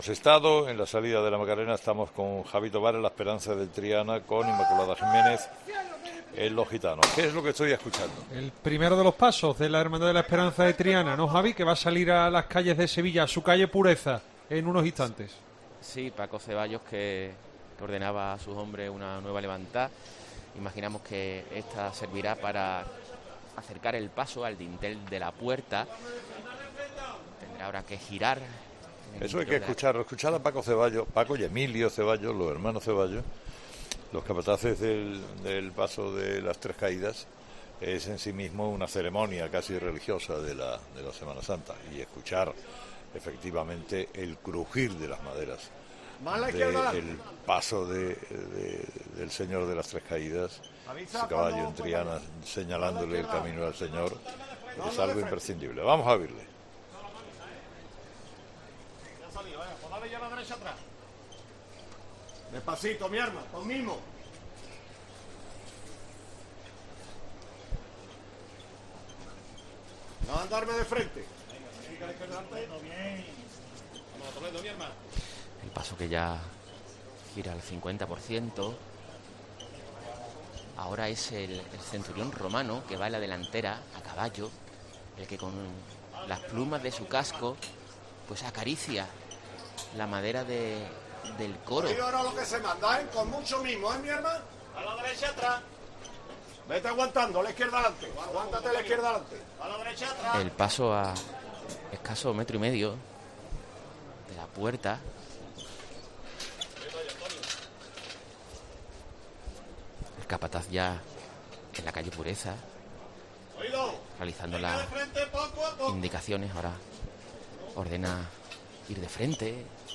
La calle Pureza, a rebosar, ha roto en aplausos con una emoción desbordada al ver su Cristo ya en la calle
La banda del Cristo lo acompaña y pone música a este momento tan emotivo. Se trata de un paso con una forma muy peculiar de caminar.